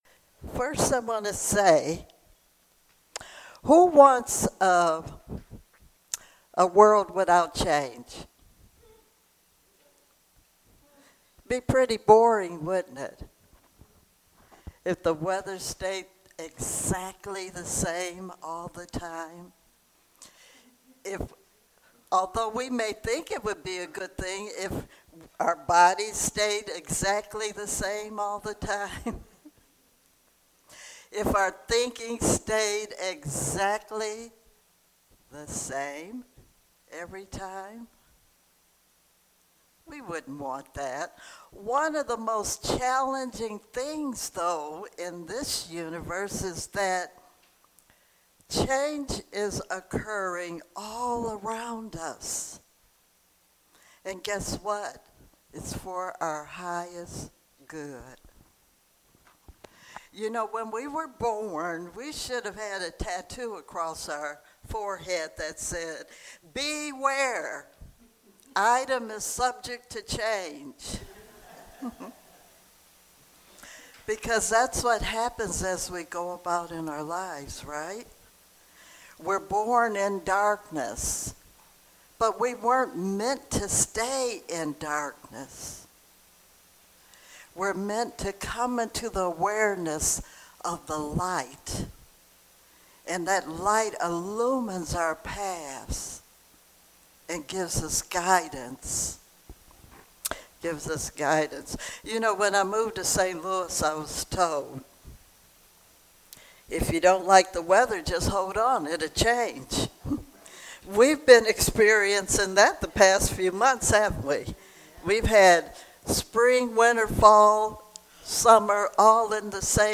Series: Sermons 2022